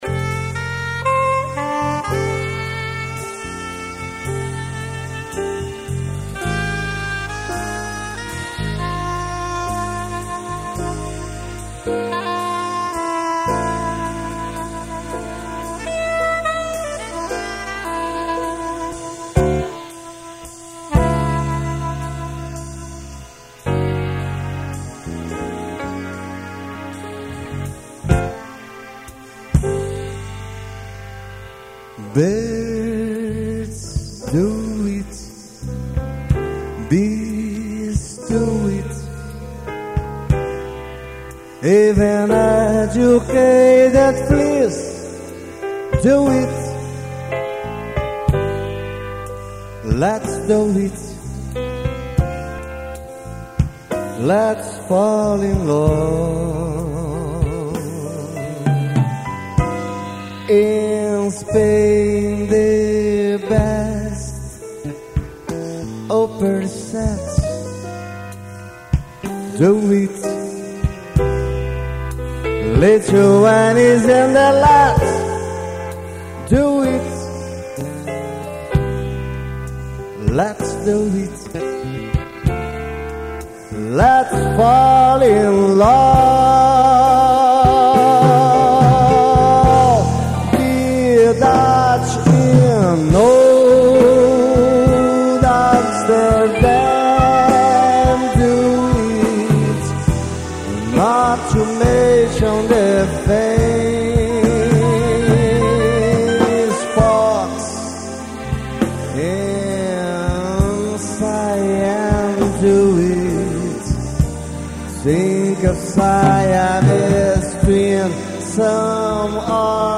139   06:24:00   Faixa:     Jazz